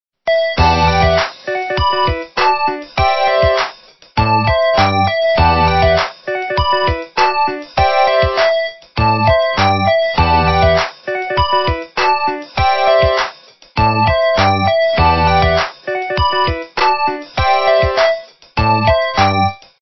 западная эстрада